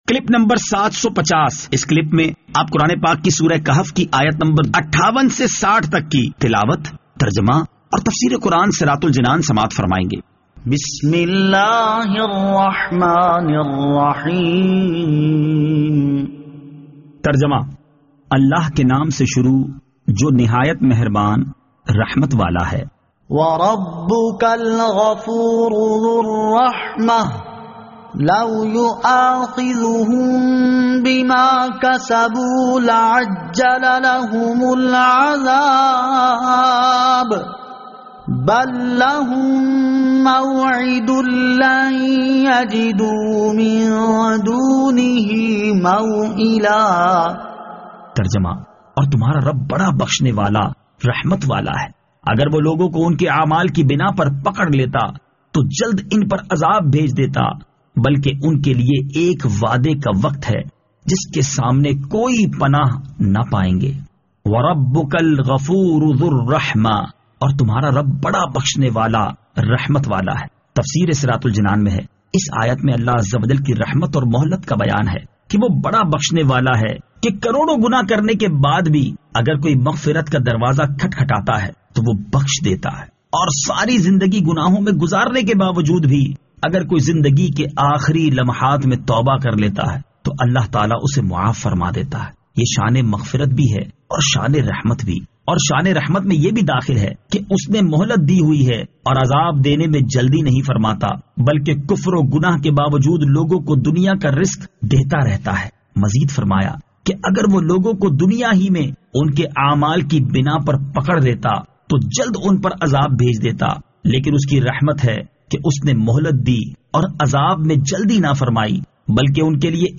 Surah Al-Kahf Ayat 58 To 60 Tilawat , Tarjama , Tafseer